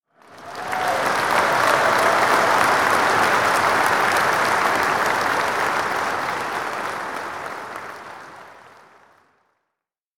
Mixkit Crowd Clapping 442 (audio/mpeg)